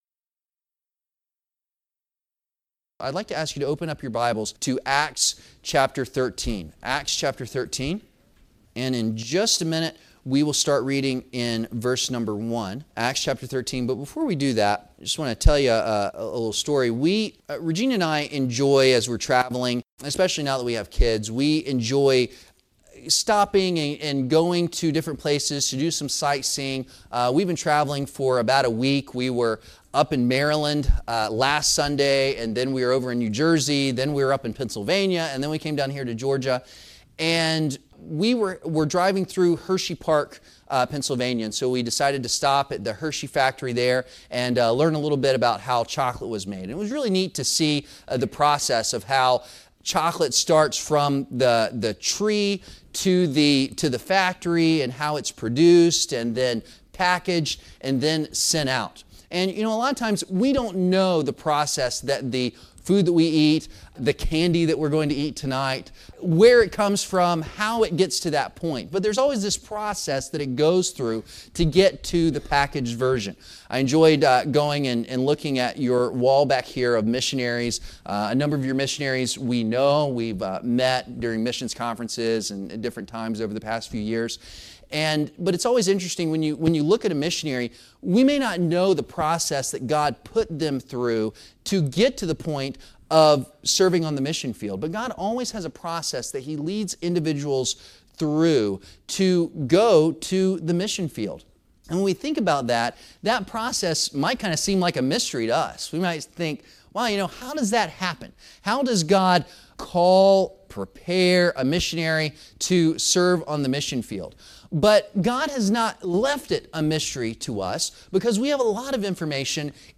Sermons from visiting guests or other speakers